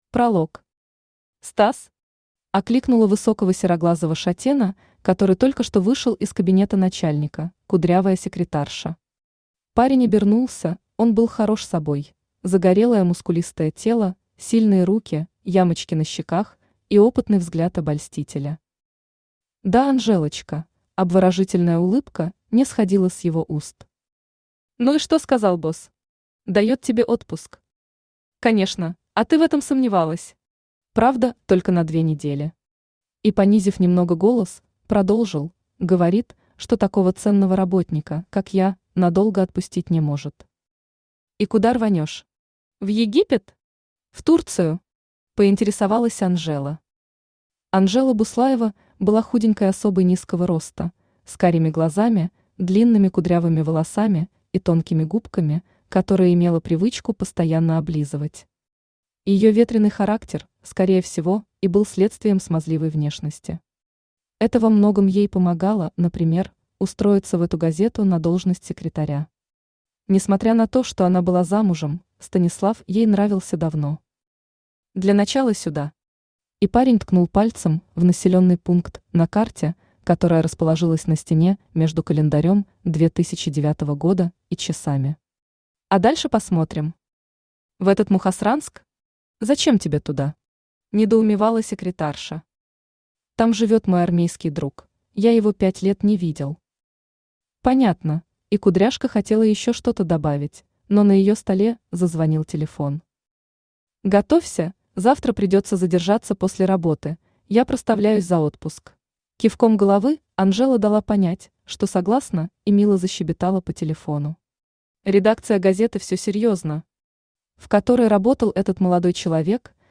Аудиокнига Молчание | Библиотека аудиокниг
Aудиокнига Молчание Автор Алена Кайзер Читает аудиокнигу Авточтец ЛитРес.